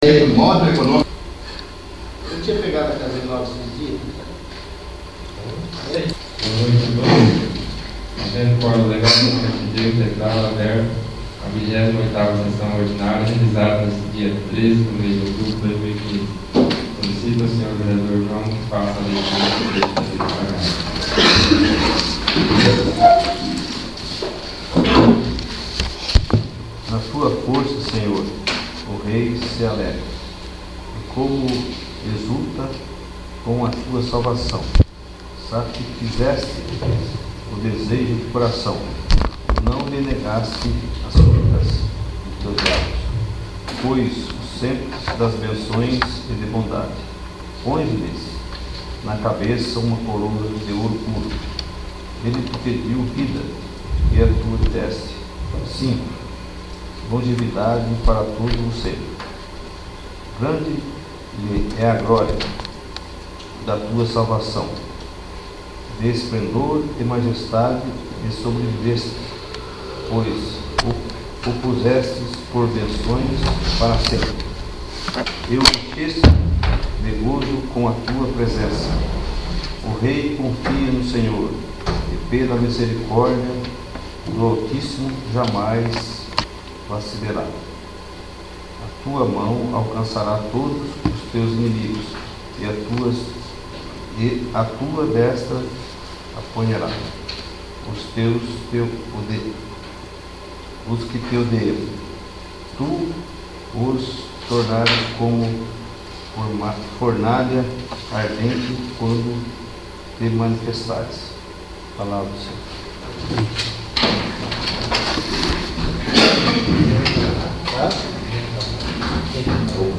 28º. Sessão Ordinária